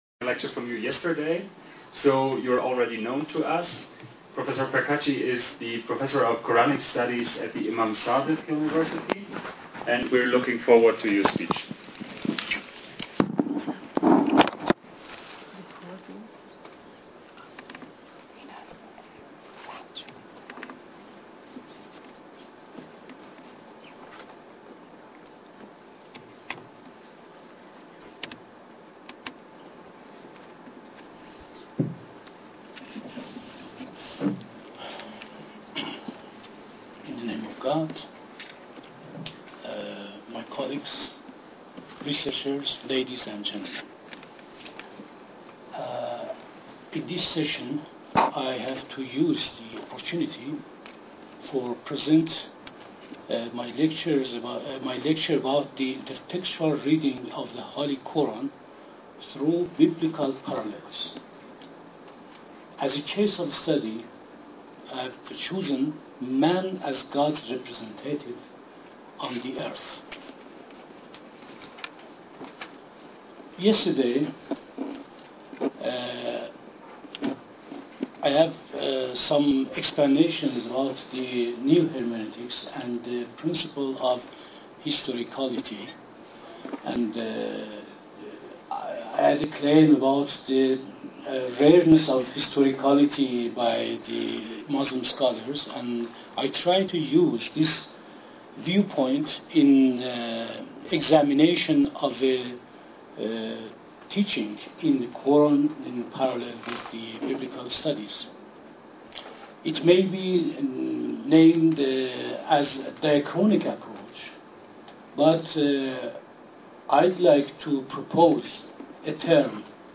سخنرانی
کارگاه آموزشی بین المللی